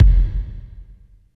31.02 KICK.wav